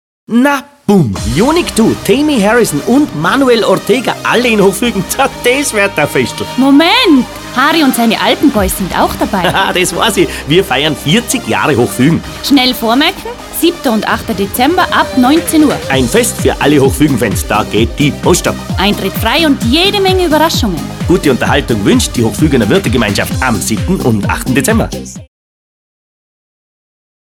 Harrys Werbespots | Harry Prünster
Harrys Werbespots Sie brauchen einen Hörfunk-Spot?